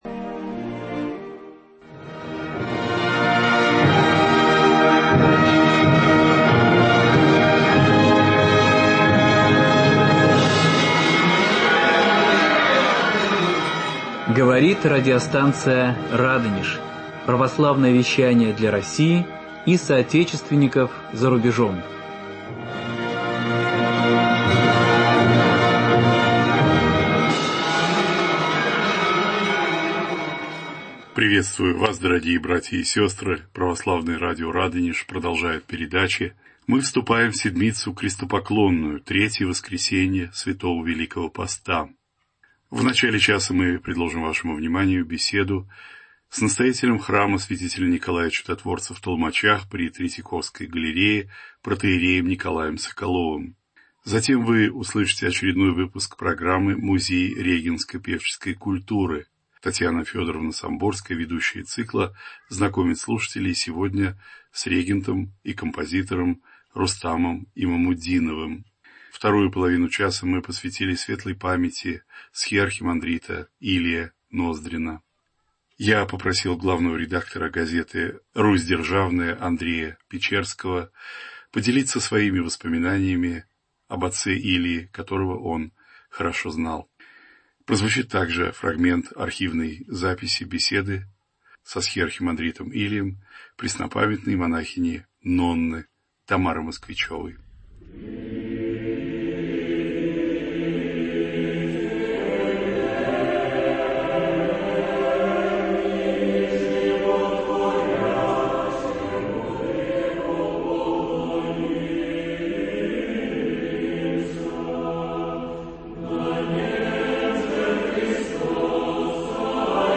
Кресту Твоему, поклоняемся, Владыко: беседа